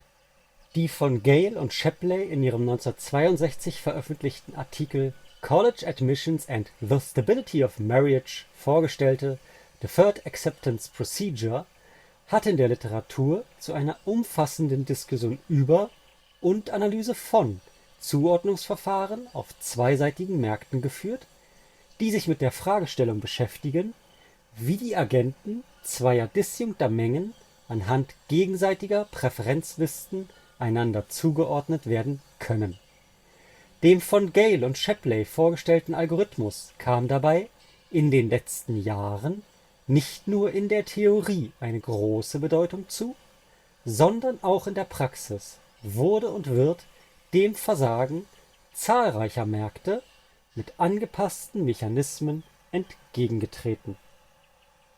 Huawei FreeBuds Pro 4 – Mikrofonqualität mit Audio-Rekorder